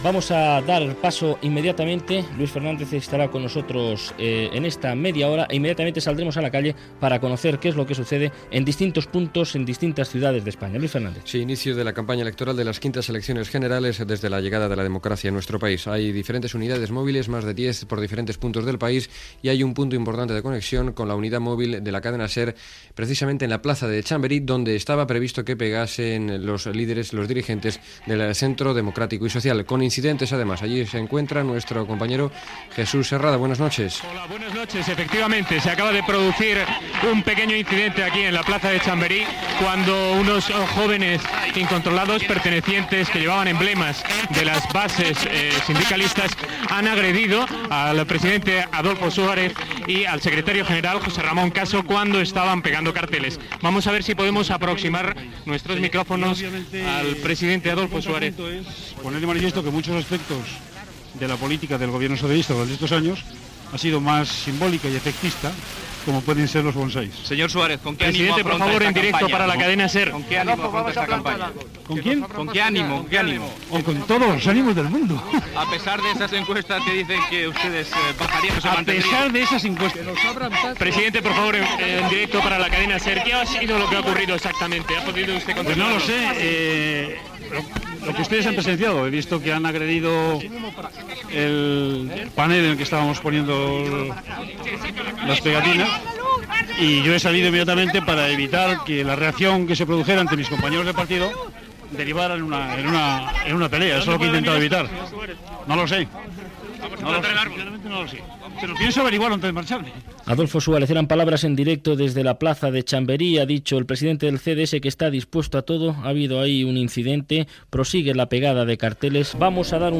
Informatiu especial la nit de l'inici de la campanya dels partits polítics que es presentaven a les eleccions generals espanyoles. Connexió amb la plaça de Chamberí de Madrid, amb declracions d'Adolfo Suárez del Centro Democrático Social (CDS), i amb la plaça de l'Obradoiro de Santiago de Compostel·la
Informatiu